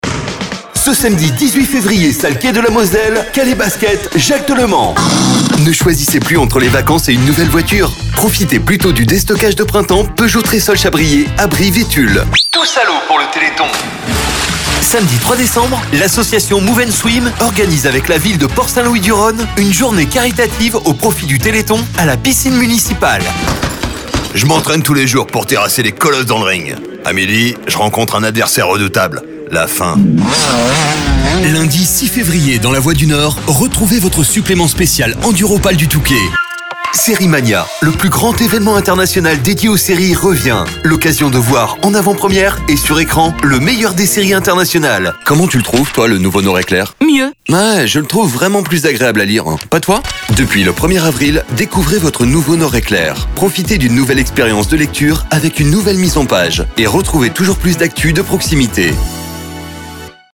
Voix off
Voix 30 - 47 ans